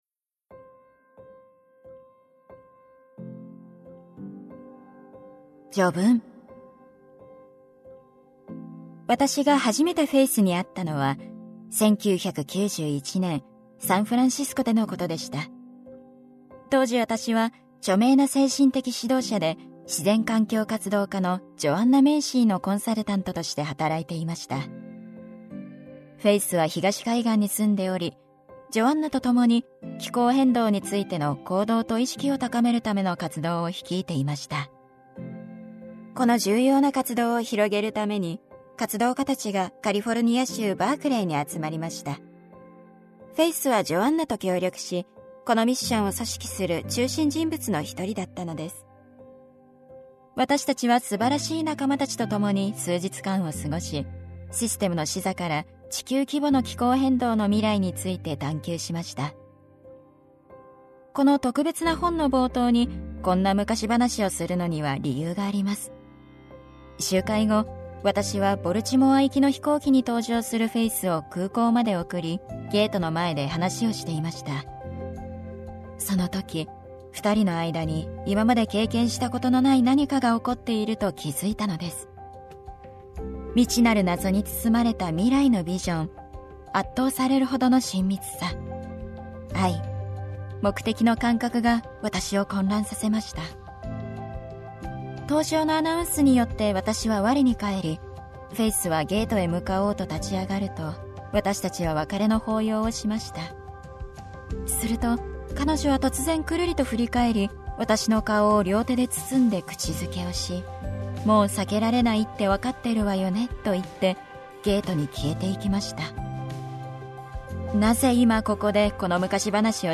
[オーディオブック] 関係性を生きる RELATIONSHIP MATTERS